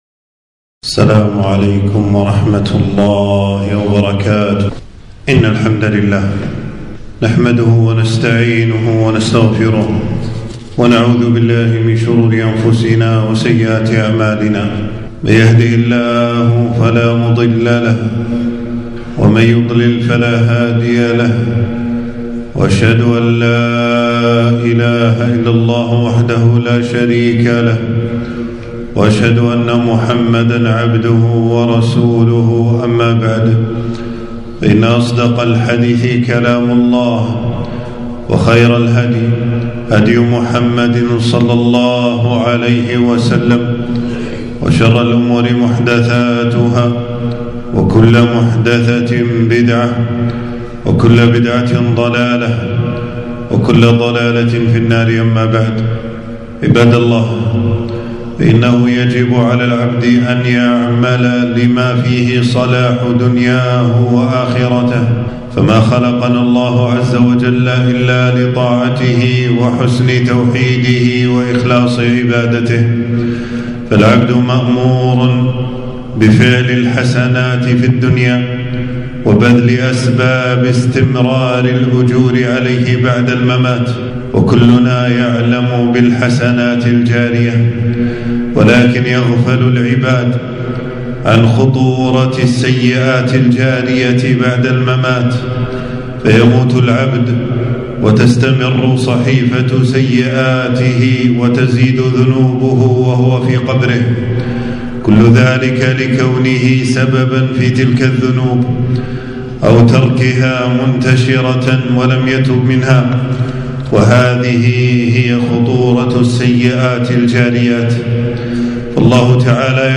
خطبة - خطورة السيئات الجارية